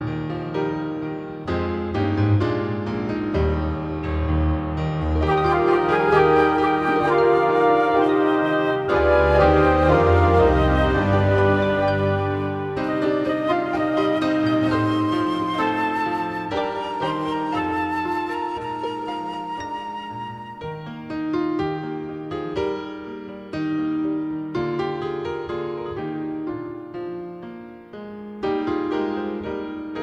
Chormusik/Evangeliumslieder